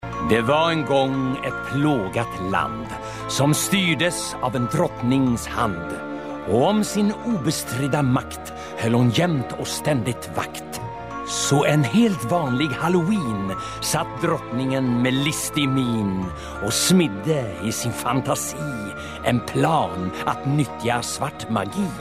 Berättaren:
halloween-berattare.mp3